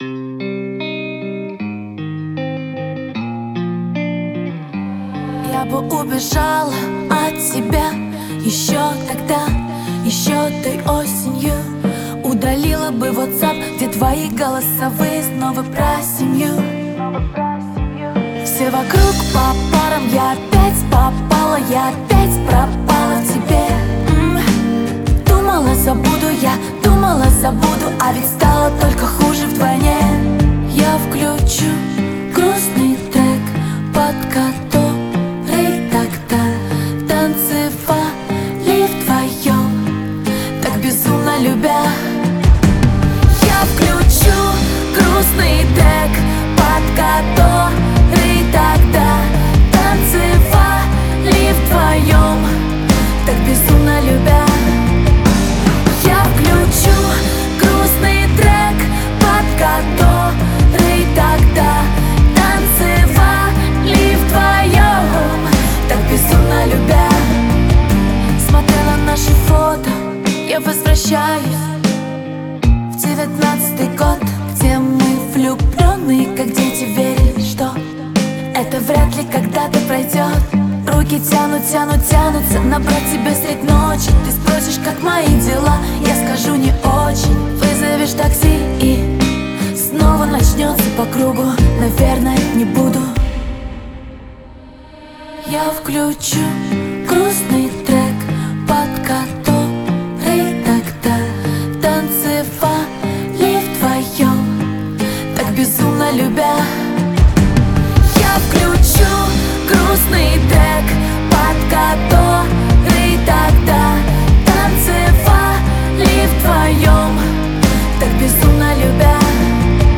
это поп-баллада, наполненная эмоциями и искренностью.